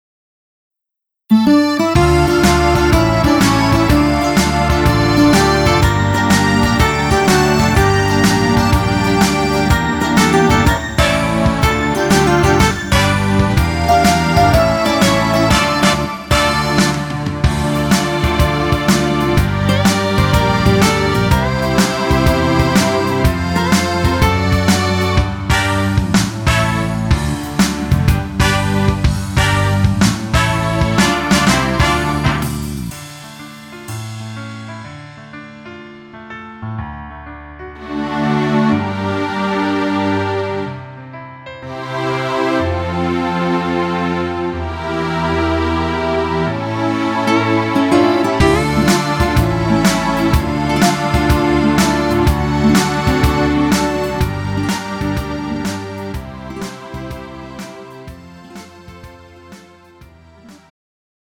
장르 가요 구분 Pro MR
Pro MR은 공연, 축가, 전문 커버 등에 적합한 고음질 반주입니다.